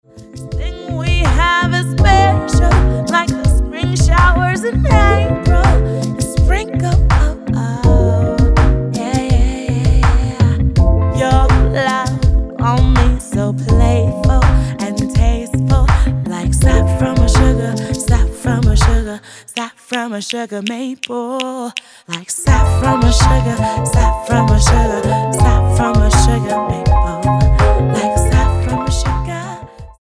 This recognition is for female vocal performance of a song.